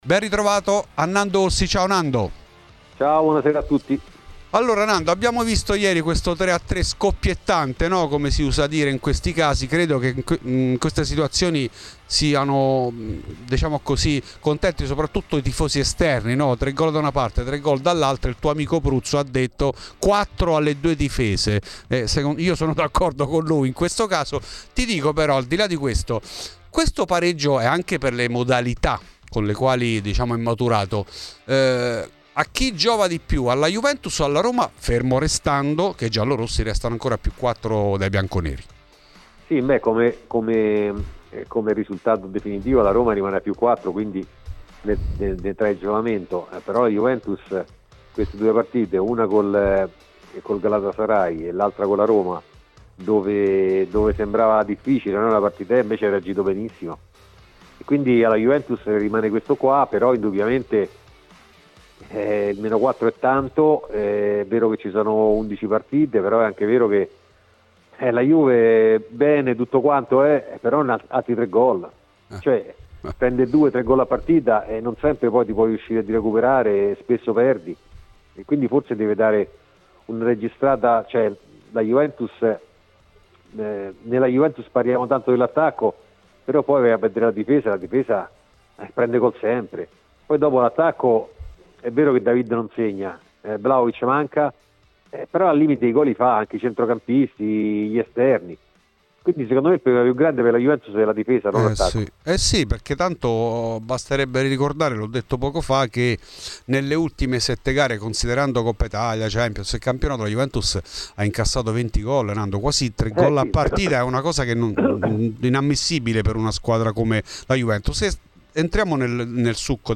trasmissione di Radio Bianconera